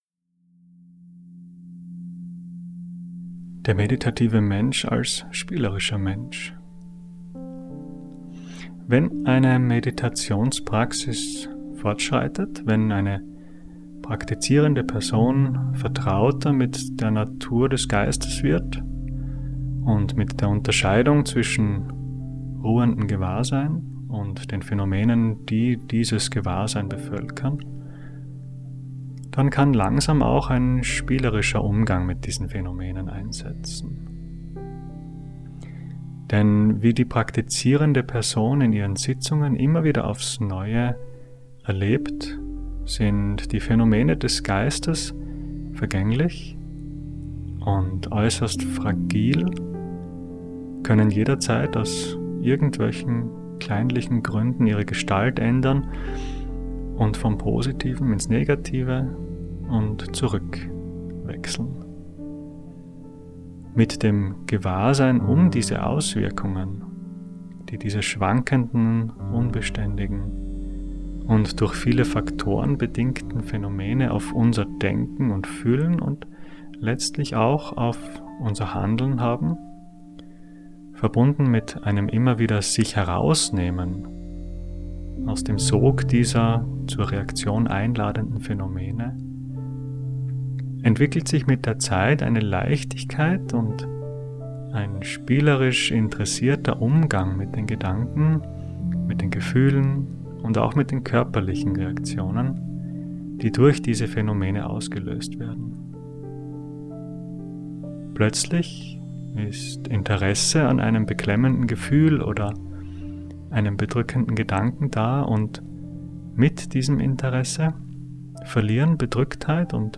Text zum Hören (04:00 Min.):